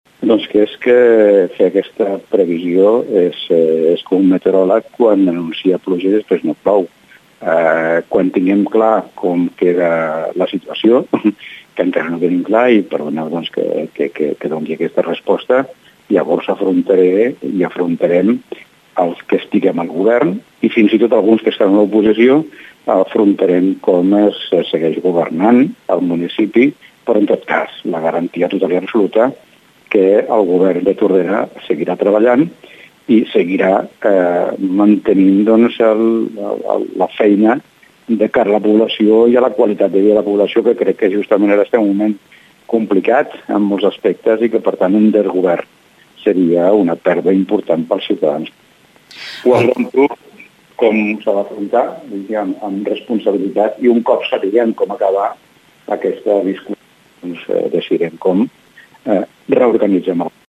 L’Alcalde de Tordera ha anunciat, durant l’entrevista dels serveis informatius de Ràdio Tordera, que per ara no té cap renuncia oficial a les carteres dels dos regidors de Som Tordera i que no signarà cap decret fins que això no passi.